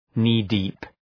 knee-deep.mp3